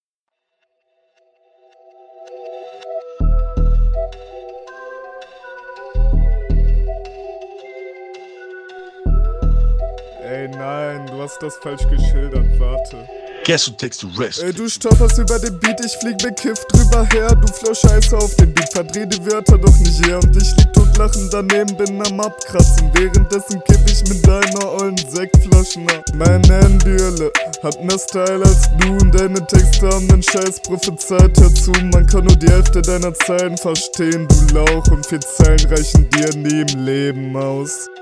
Coole Klangfarbe aber auch wieder unverständlich.